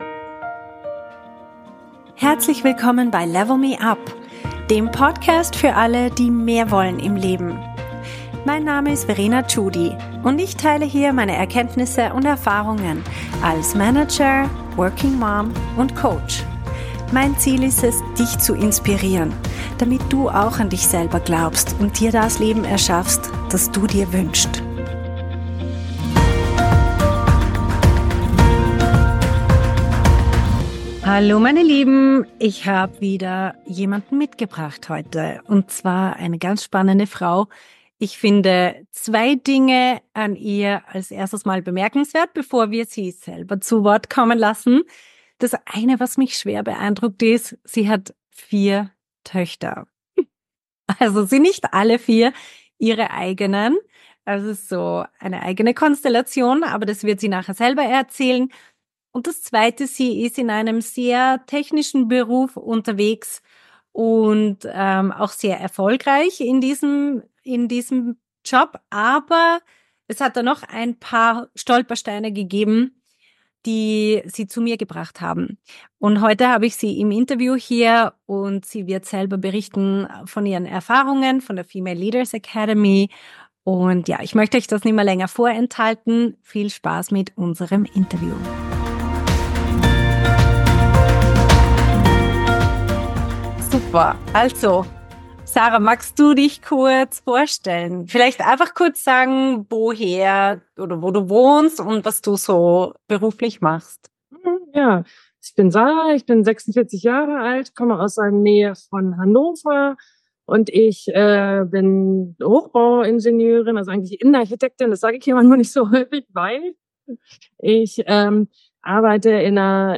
Mut zur Veränderung - Interview